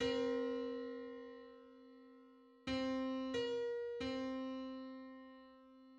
File:Four-hundred-sixty-fifth harmonic on C.mid - Wikimedia Commons
Public domain Public domain false false This media depicts a musical interval outside of a specific musical context.
Four-hundred-sixty-fifth_harmonic_on_C.mid.mp3